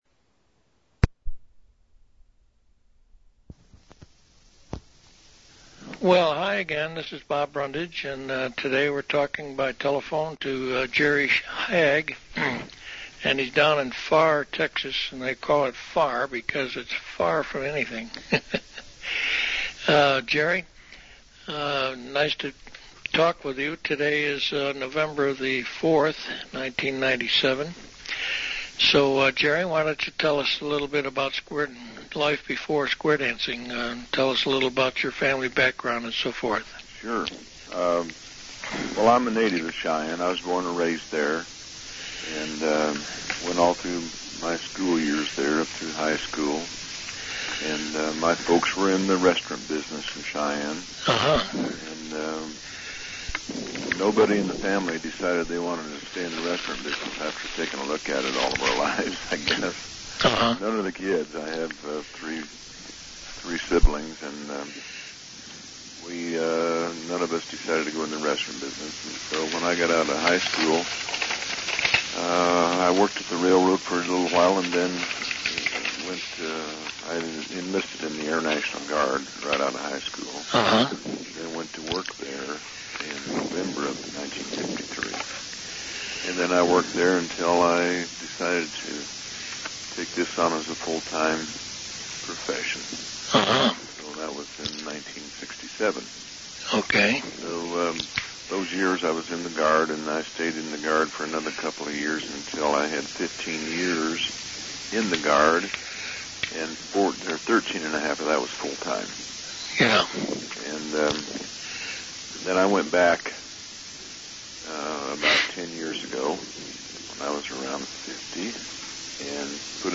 2016 Interview Transcripts